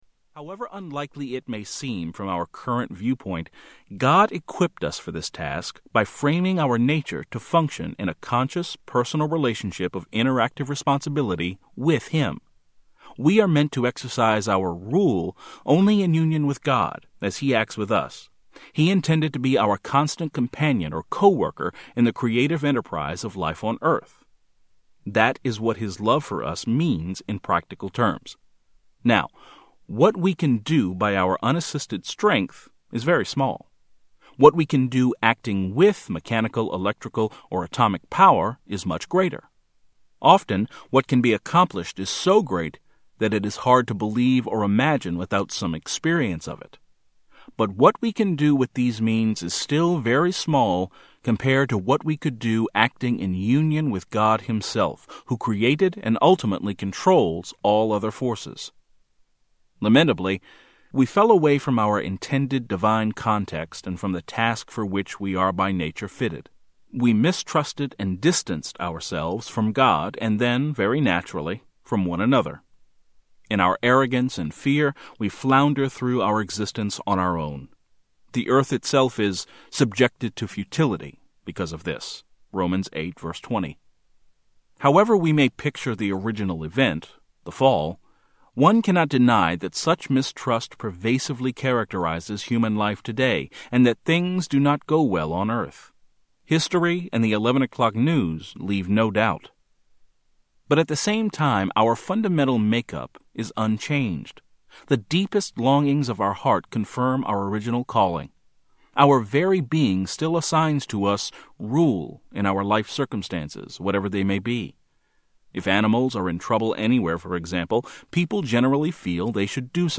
Tags: Media Writer Christian Christian audio books Audio books